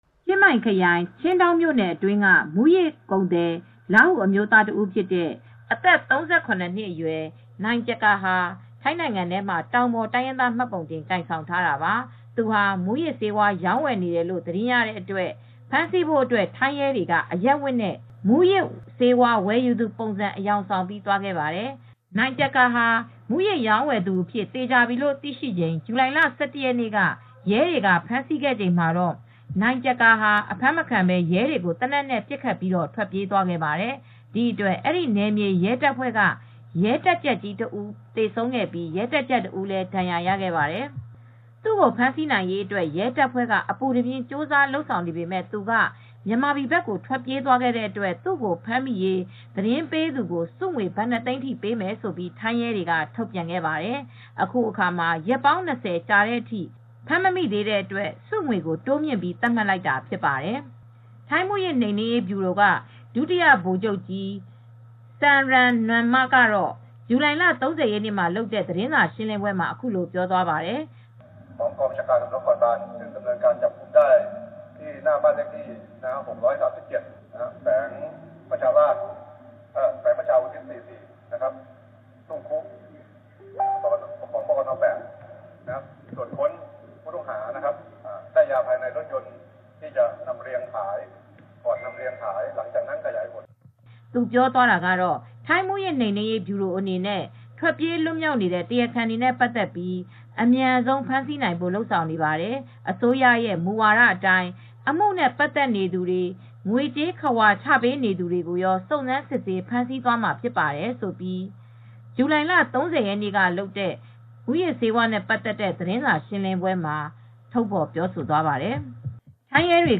ထိုင်းမူးယစ်နှိမ်နင်းရေး ဗျူရို ဒုတိယဗိုလ်ချုပ် Pol. Lt. Gen. Samran Nuanma ကတော့ ဇူလိုင် ၃၀ရက်နေ့မှာလုပ်တဲ့သတင်းစာရှင်းလင်းပွဲမှာအခုလိုပြောသွားပါတယ်။